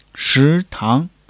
shi2--tang2.mp3